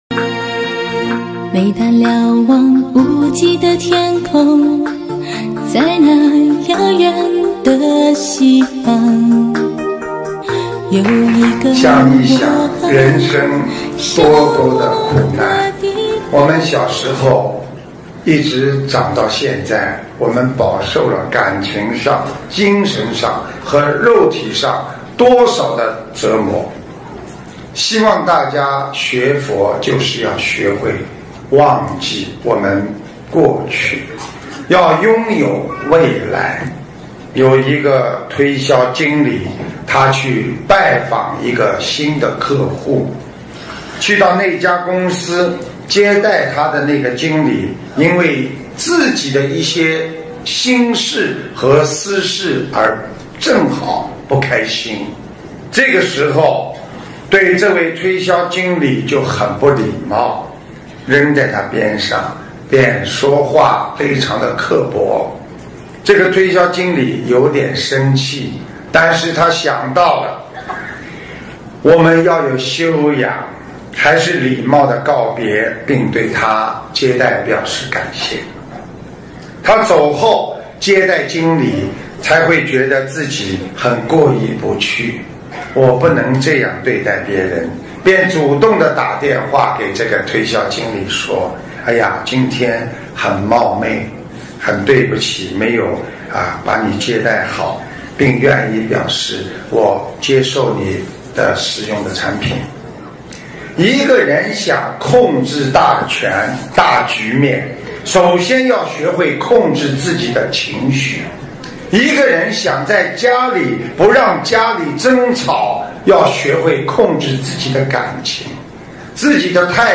音频：懂得惜缘！洒脱随缘！2014年9月13日！台北世界佛友见面会！